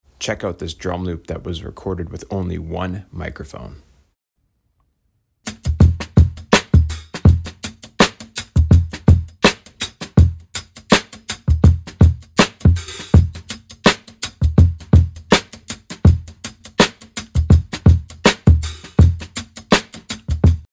Check oit this drum loop sound effects free download
Check oit this drum loop that was made using a single microphone.